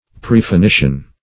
Search Result for " prefinition" : The Collaborative International Dictionary of English v.0.48: Prefinition \Pref`i*ni"tion\, n. [L. praefinitio.]